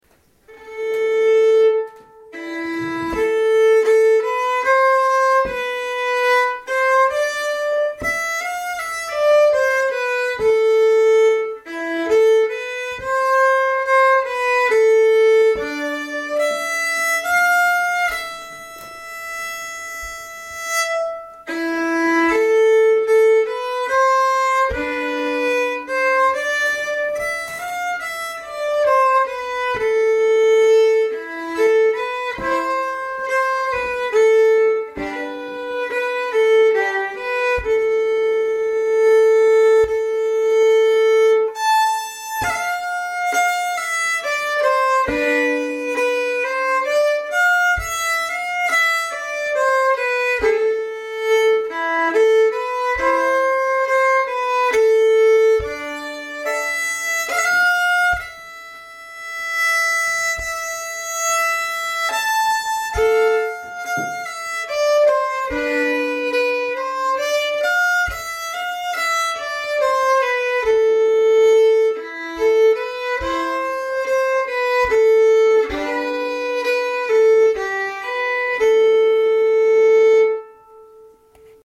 Spelstuga
Vals efter Kalle Liljeberg "långsamt"